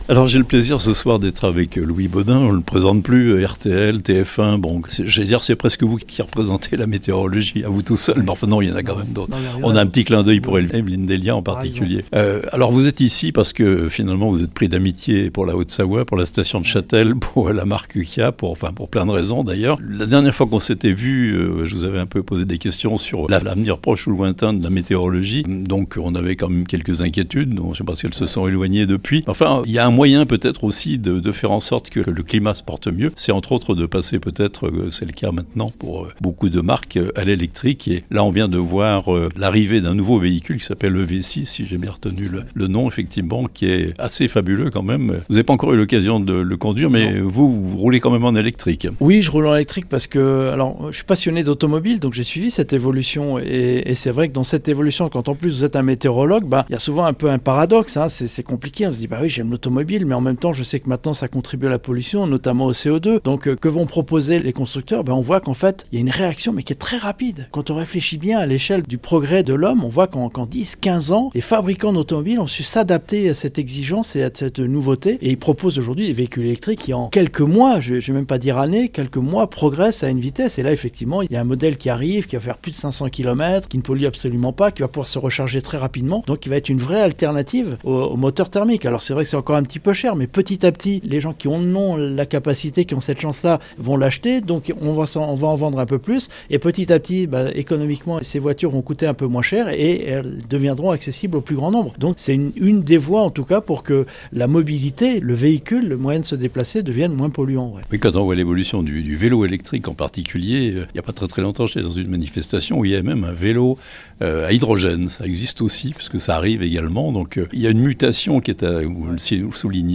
Il était présent à Margencel, à la concession automobile du constructeur coréen Kia, le mercredi 27 octobre, pour la présentation du dernier modèle entièrement électrique de la marque, baptisé EV6. L’occasion de lui demander son sentiment sur la mutation de l’industrie automobile face au changement climatique.